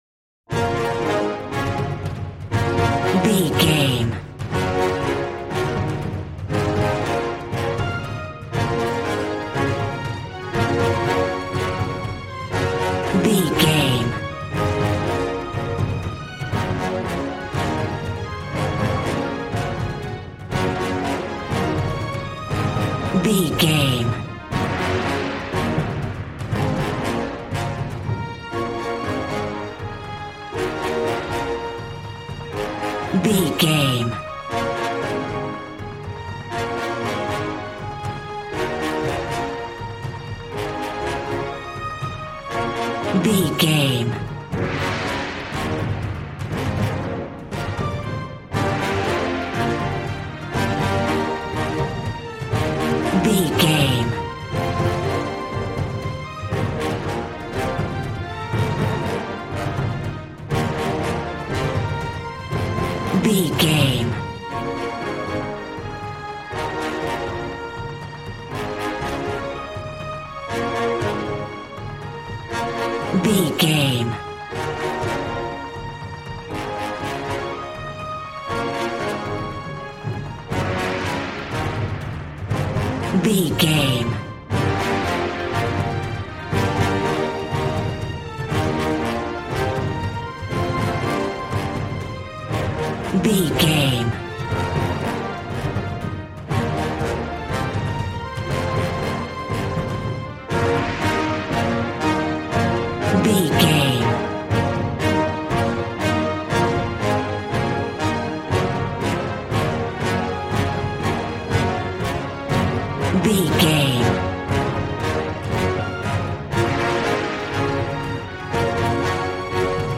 Action and Fantasy music for an epic dramatic world!
Aeolian/Minor
B♭
hard
groovy
drums
bass guitar
electric guitar